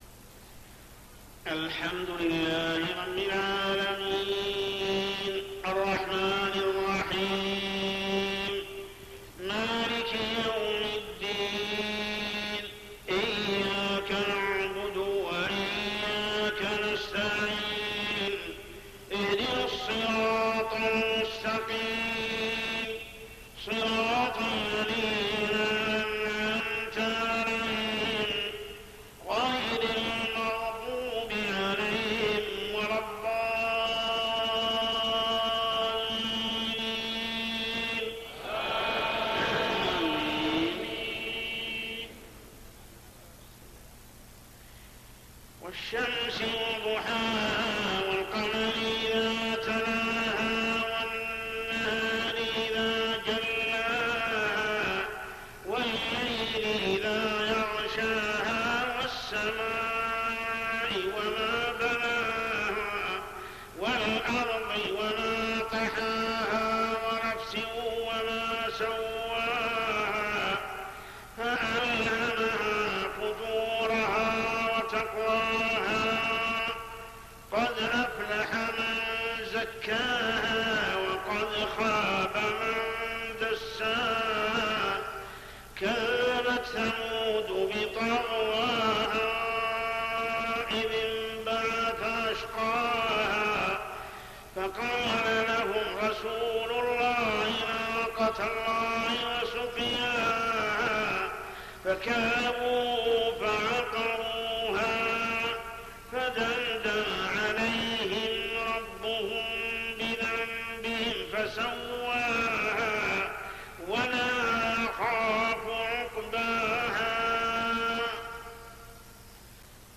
صلاة العشاء 5-9-1423هـ سورتي الشمس و الزلزلة كاملة | Isha prayer Surah Ash-Shams and Az-Zalzalah > 1423 🕋 > الفروض - تلاوات الحرمين